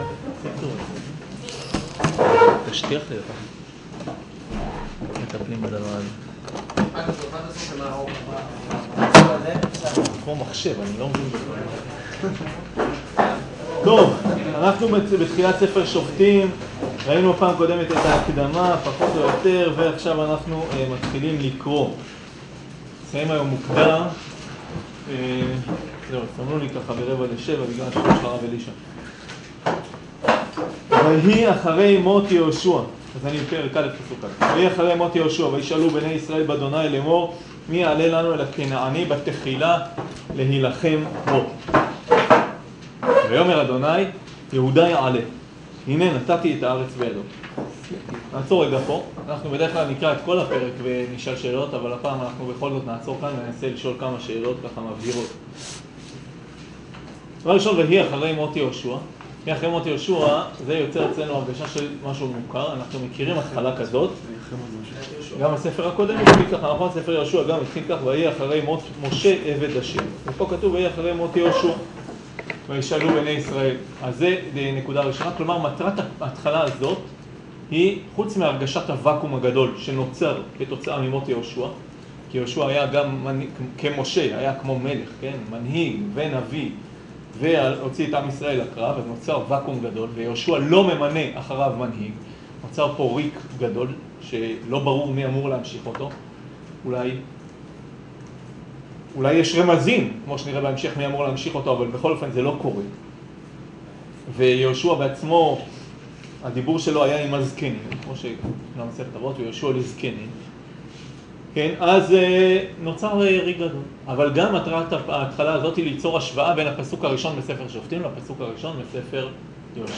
שיעור פרק א